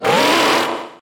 wyrdeer_ambient.ogg